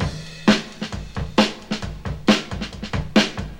• 133 Bpm Modern Drum Beat G# Key.wav
Free drum groove - kick tuned to the G# note. Loudest frequency: 1449Hz
133-bpm-modern-drum-beat-g-sharp-key-xur.wav